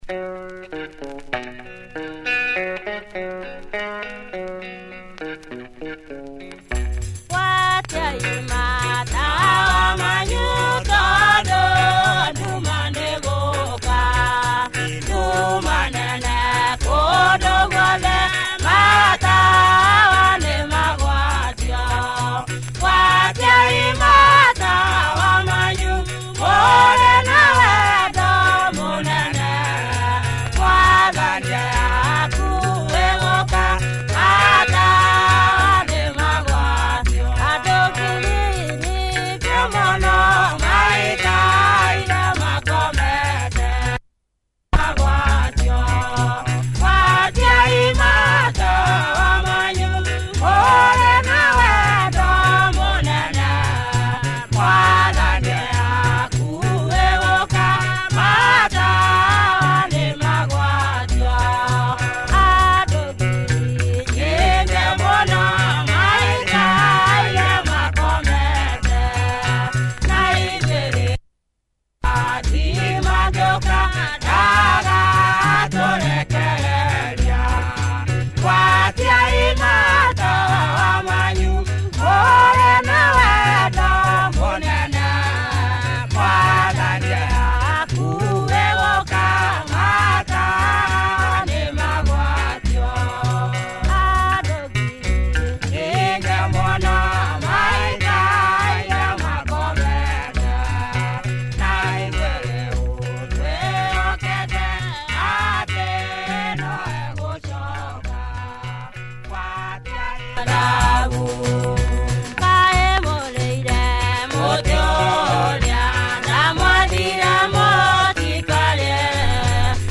Cool rural groove here in typical kikuyu style